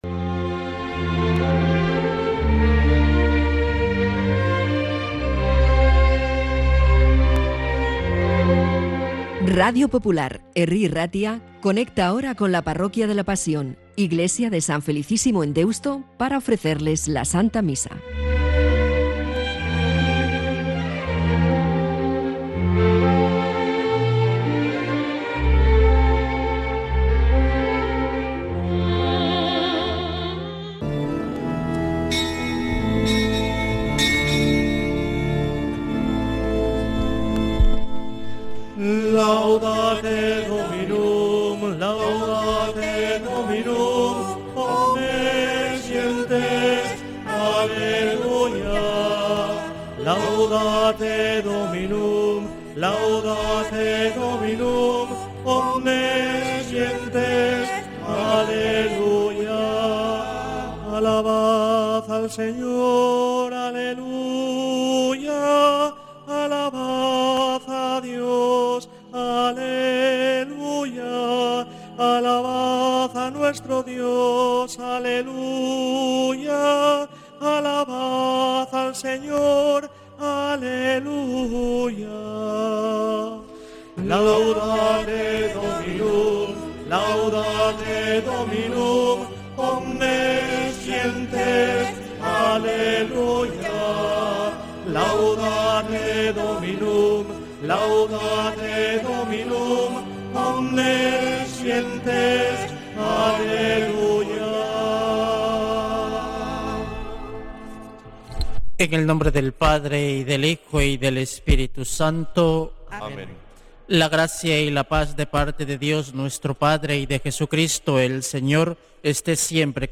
Santa Misa desde San Felicísimo en Deusto, domingo 28 de septiembre de 2025